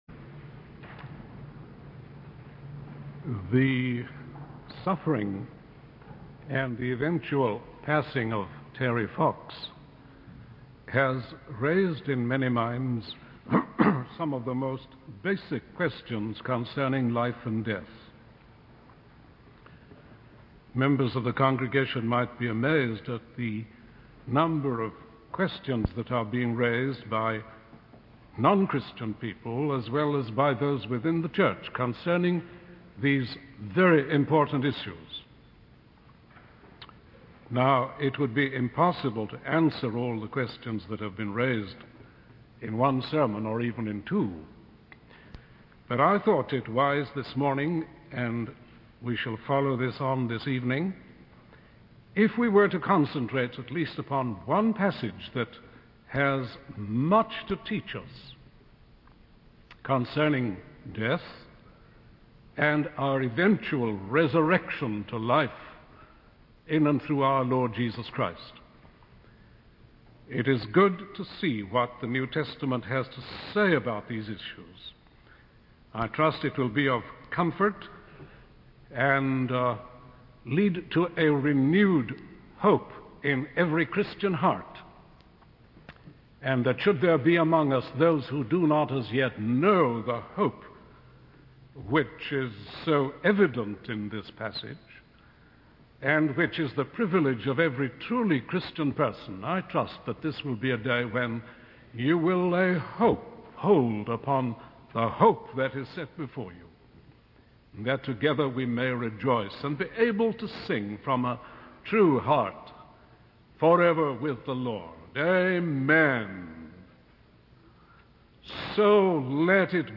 In this sermon, the preacher discusses the concept of our physical bodies as temporary dwellings, comparing them to tents that wear out. He emphasizes the importance of believing in the revelation of God and having hope for the future.